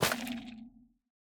Minecraft Version Minecraft Version snapshot Latest Release | Latest Snapshot snapshot / assets / minecraft / sounds / block / sculk_vein / break1.ogg Compare With Compare With Latest Release | Latest Snapshot
break1.ogg